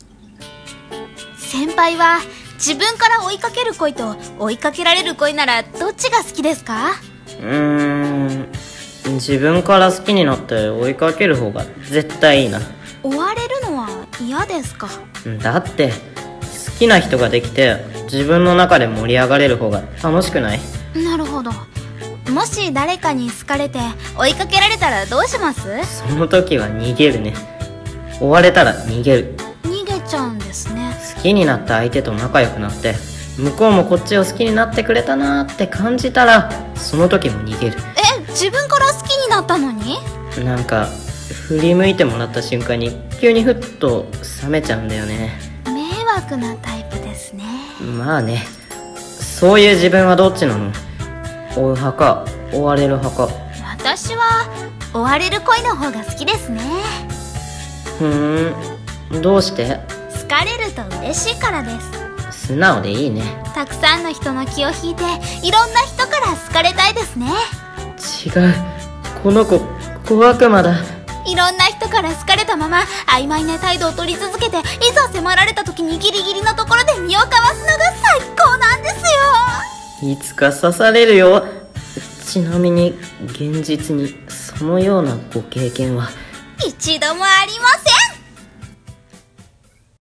【声劇台本】恋バナ